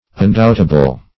Undoubtable \Un*doubt"a*ble\, a.
undoubtable.mp3